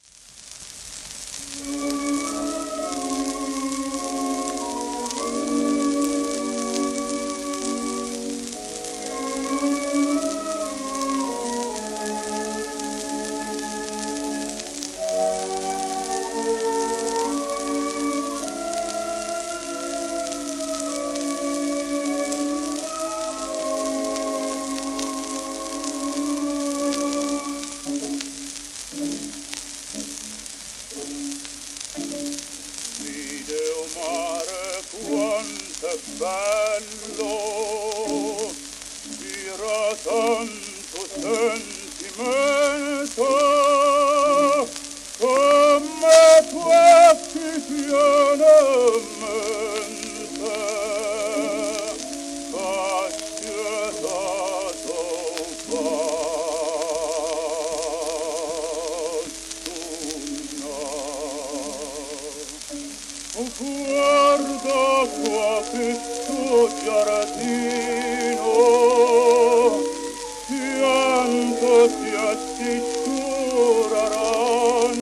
w/オーケストラ
旧 旧吹込みの略、電気録音以前の機械式録音盤（ラッパ吹込み）